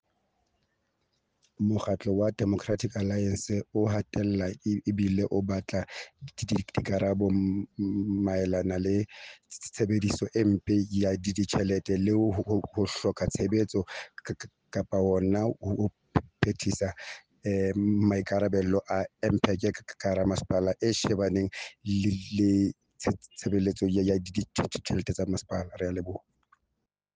Sesotho soundbites by Cllr Thulani Mbana and